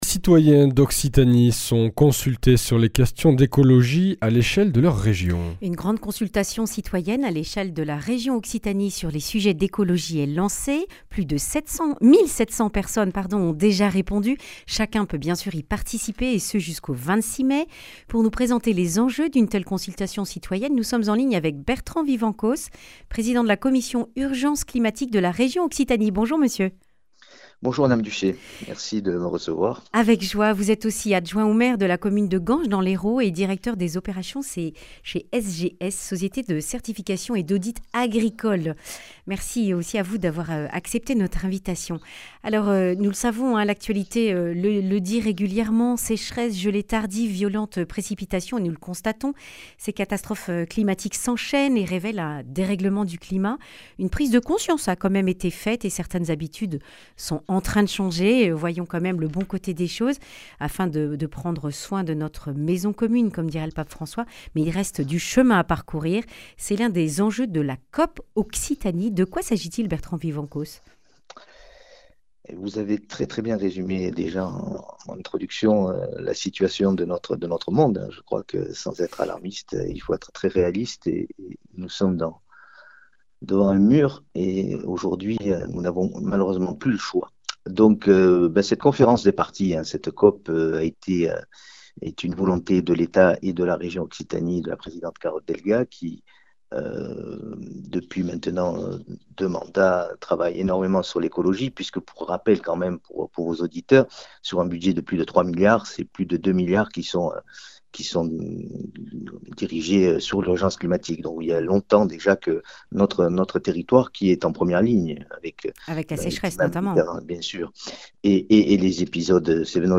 Préservation des ressources en eau et de la biodiversité, logement, gestion des déchets, déplacements sont quelques thématiques parmi les neuf sélectionnées pour la consultation citoyenne sur l’écologie à l’échelle de l’Occitanie. Bertrand Vivencos, vice-président de la commission "Urgence climatique" de la région Occitanie.
Accueil \ Emissions \ Information \ Régionale \ Le grand entretien \ Consultation citoyenne sur l’écologie, à vos clics !